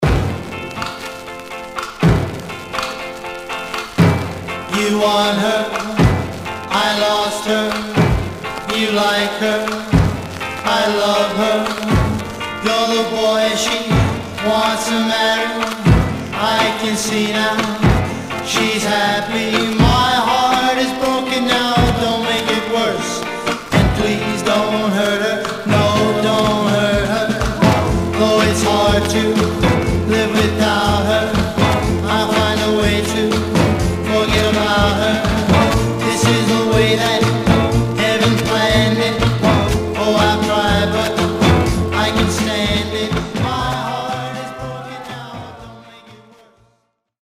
Surface noise/wear Stereo/mono Mono
Teen